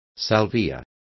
Complete with pronunciation of the translation of salvia.